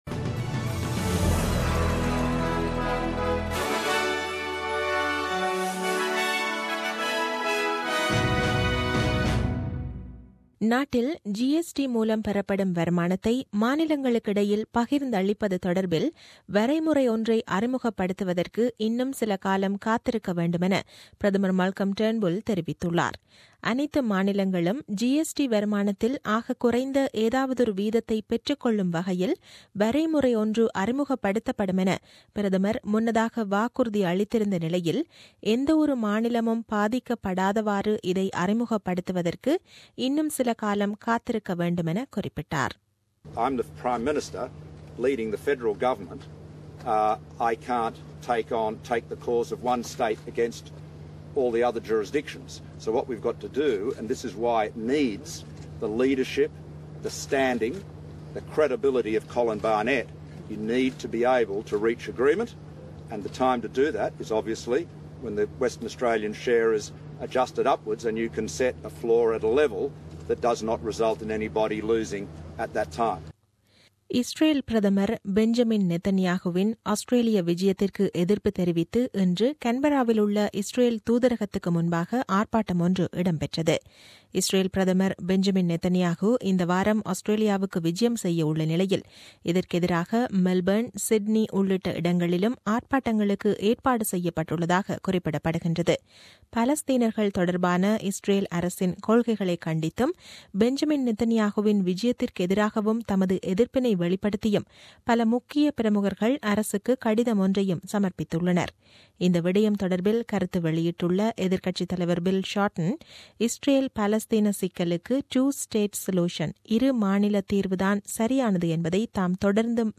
The news bulletin aired on 20 Feb 2017 at 8pm.